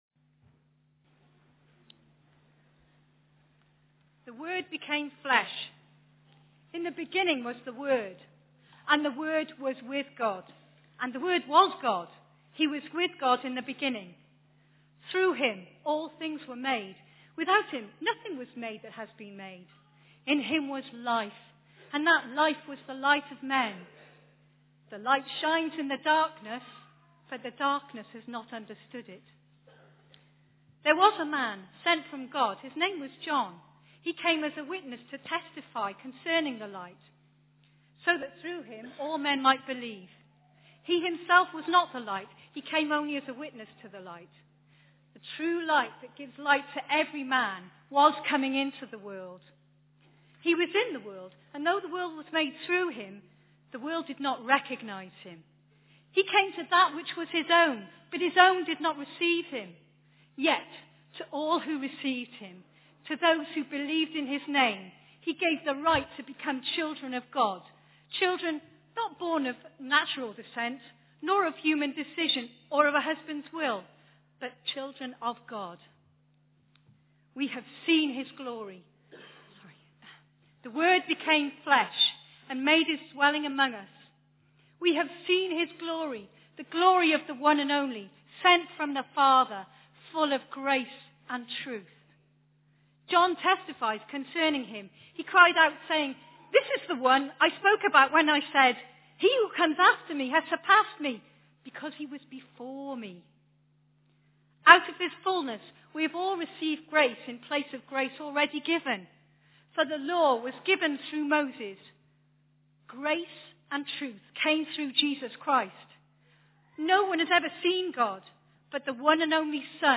Theme – Carol Service